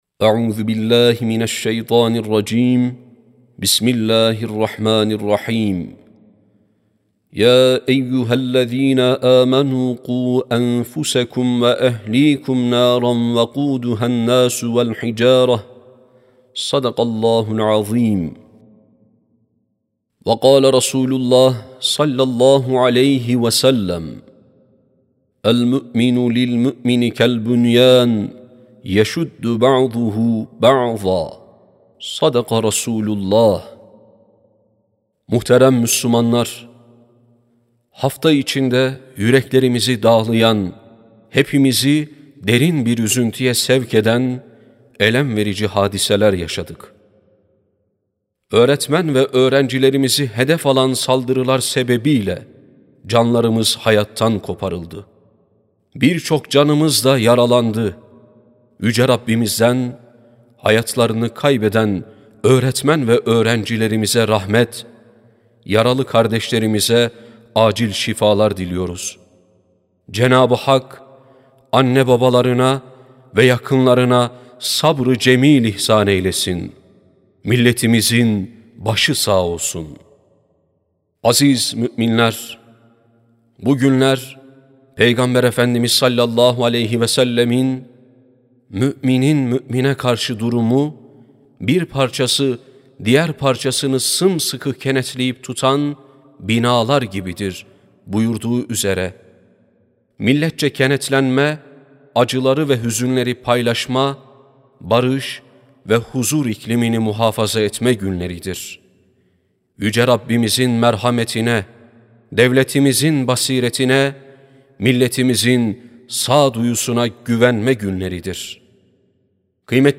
17.04.2026 Cuma Hutbesi: Birbirimize Kenetlenelim, Sorumluluklarımızı İdrak Edelim (Sesli Hutbe, Türkçe, İspanyolca, İtalyanca, Rusça, İngilizce, Arapça, Almanca, Fransızca)
Sesli Hutbe(Birbirimize Kenetlenelim, Sorumluluklarımızı İdrak Edelim).mp3